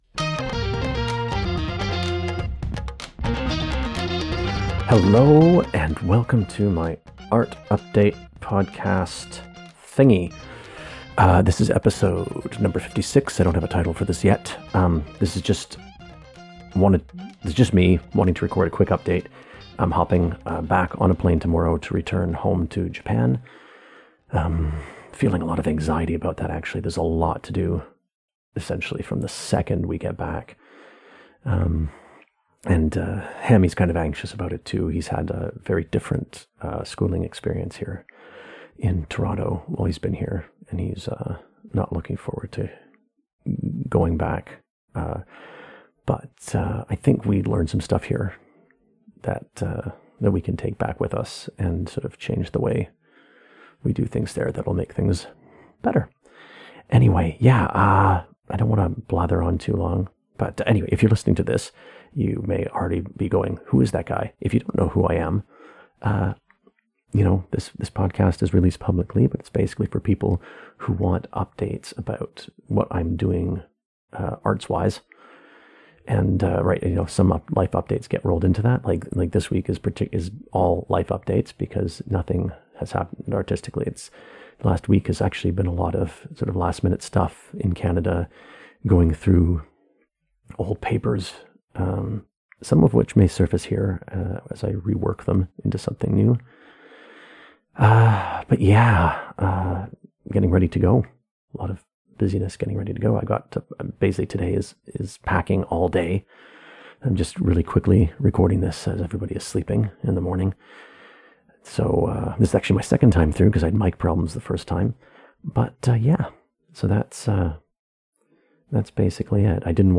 I think I said “update number 56” in the episode, but I’d already dismantled my mic, so we’ll just have to live with it…   Just a quick update before I fly back to Yokohama.